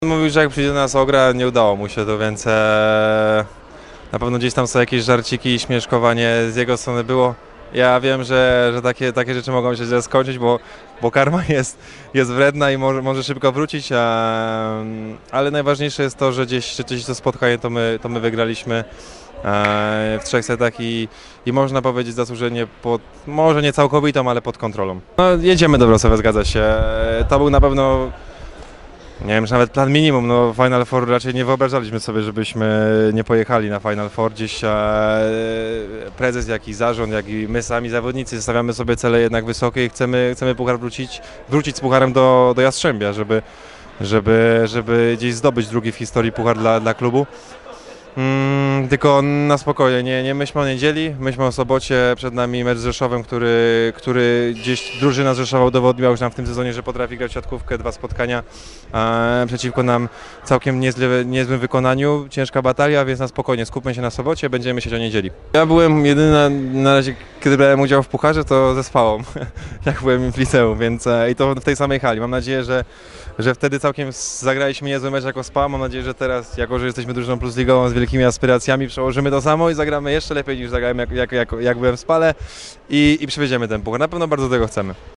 Tomasz Fornal po wygranym meczu z Projektem Warszawa i awansie do turnieju finałowego Tauron Pucharu Polski